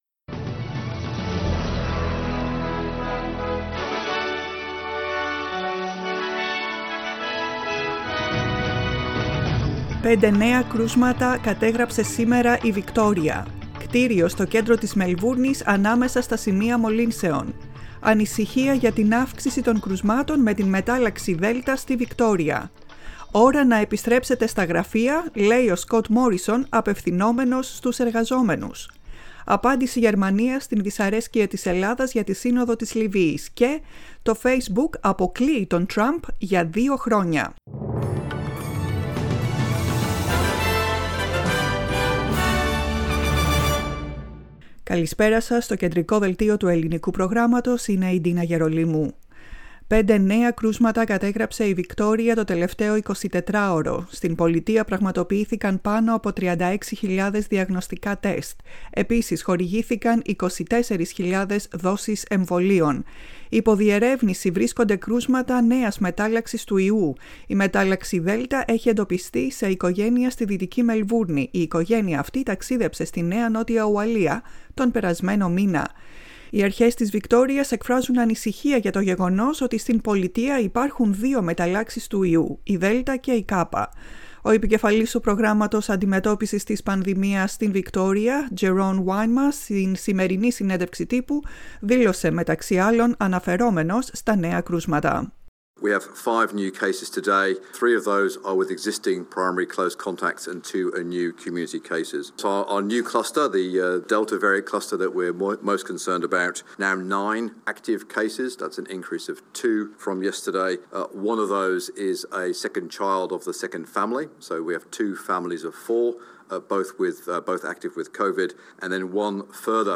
Δελτίο ειδήσεων, 05.06.21
Το κεντρικό δελτίο ειδήσεων του Ελληνικού Προγράμματος.